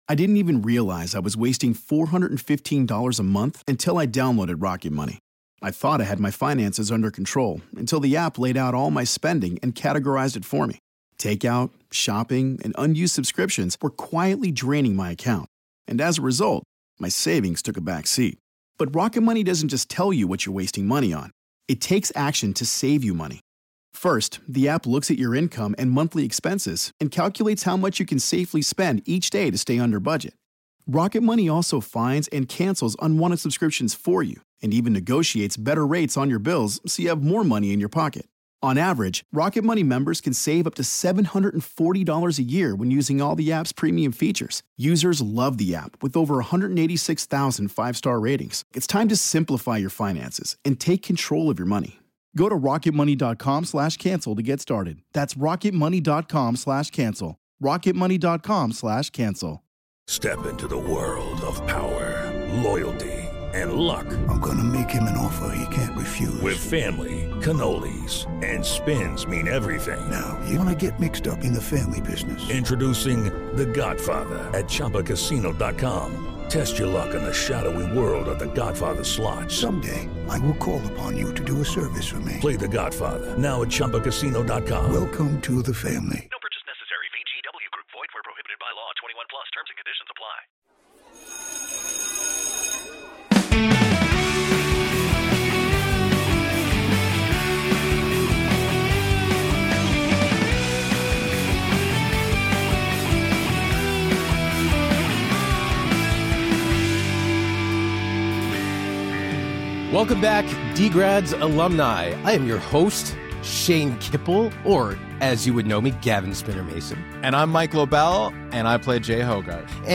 Credits: Hosts/Producers - Shane Kippel and Mike Lobel